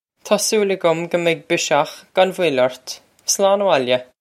Taw sool a-gum guh meg bish-okh gon vwill urt. Slawn a-wohl-ya!
This is an approximate phonetic pronunciation of the phrase.